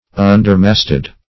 Search Result for " undermasted" : The Collaborative International Dictionary of English v.0.48: Undermasted \Un"der*mast`ed\, a. (Naut.) Having masts smaller than the usual dimension; -- said of vessels.